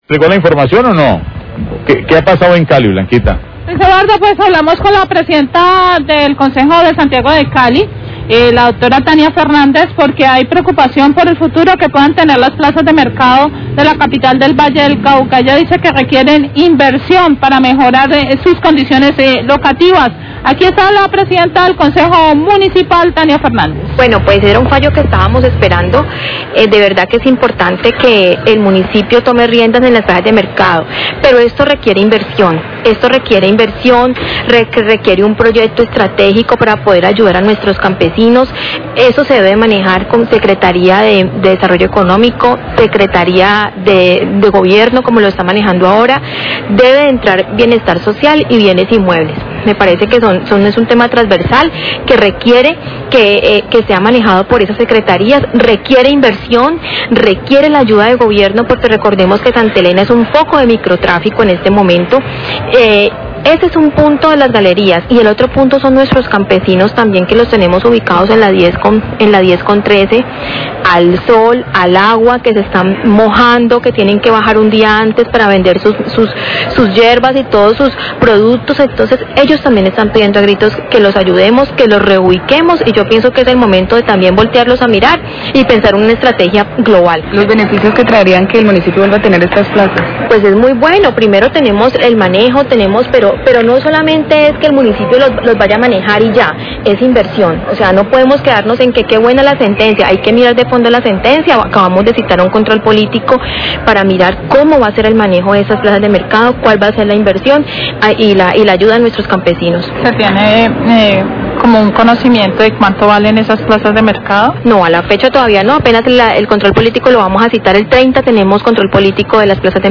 PRESIDENTE DE CONCEJO HABLÓ SOBRE MANEJO DE PLAZAS DE MERCADO, RADIO CALIDAD, 12.34pm
NOTICIAS DE CALIDAD